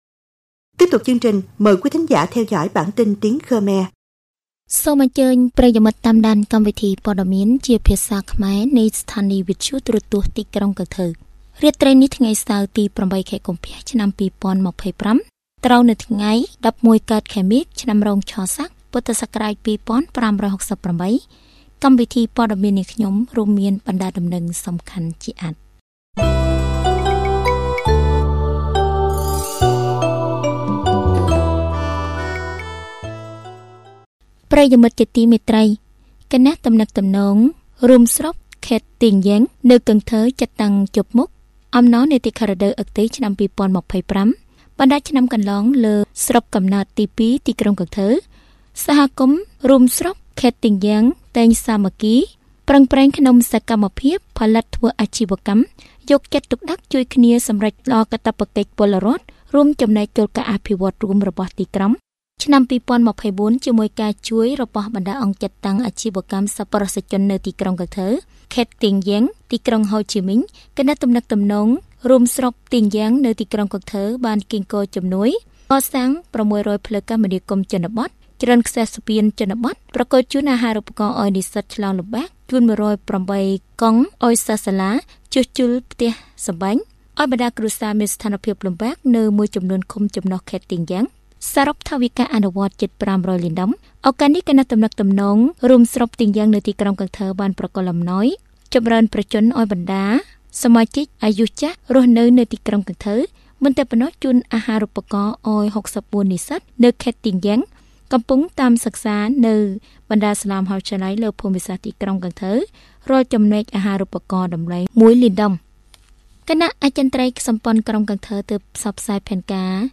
Bản tin tiếng Khmer tối 8/2/2025